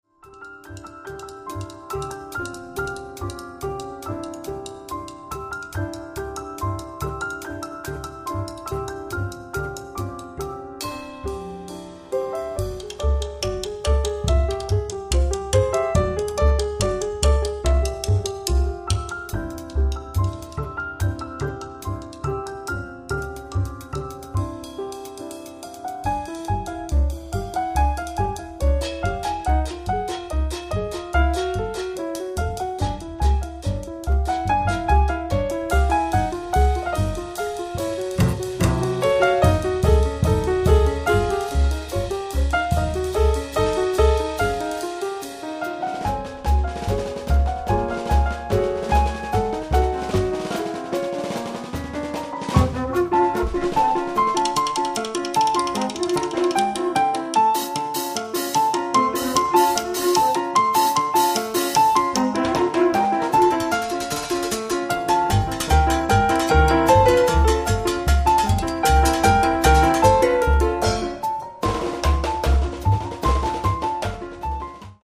piano
batteria
contrabbasso
ovviamente in chiave jazz